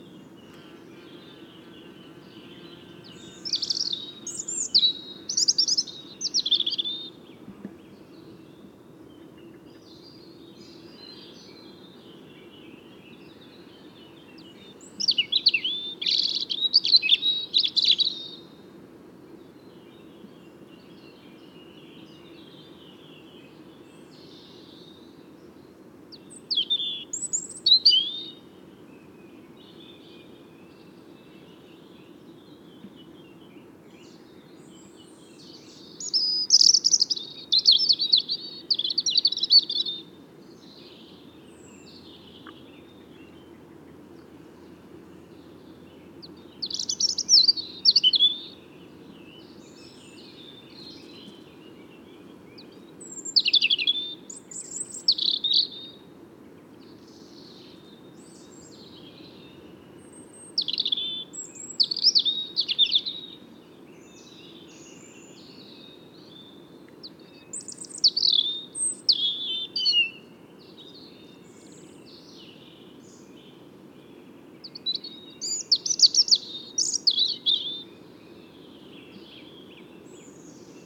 Rotkehlchen
Hören Sie hier die Stimme des Rotkehlchens.
Rotkehlchen.mp3